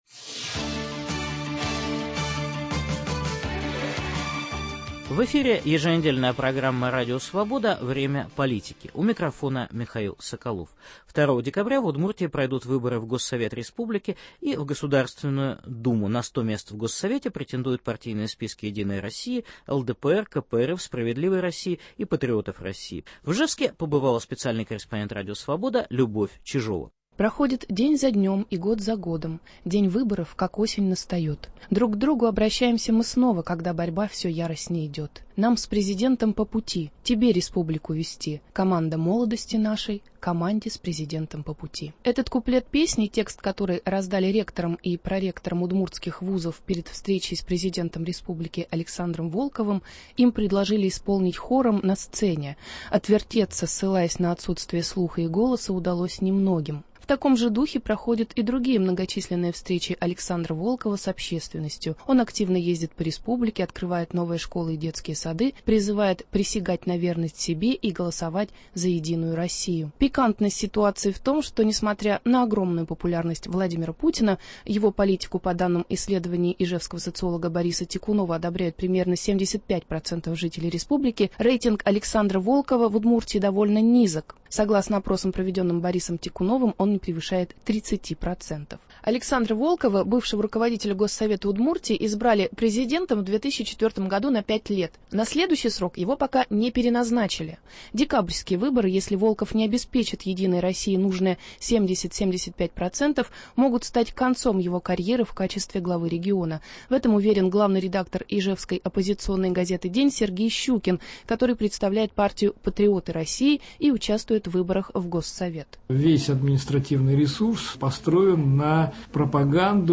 Предвыборный репортаж из Удмуртии